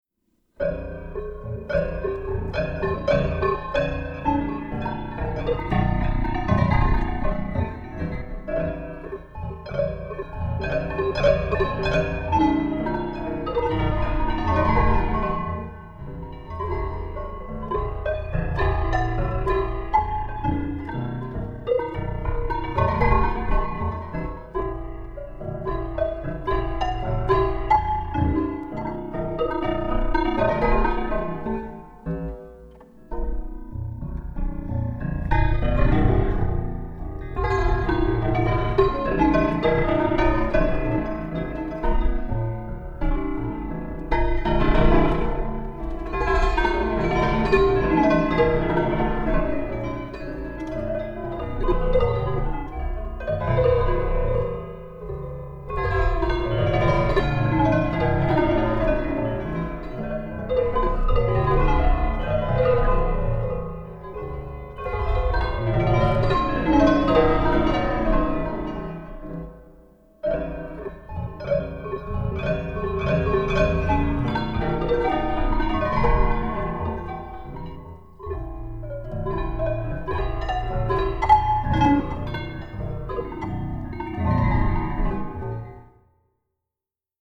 The clumsy story of a horse and a prince. Modulated by a piano, showered through some old and new electrical circuits, turning the fake into a renovated action of pure attraction.
FortePianoIncedere.mp3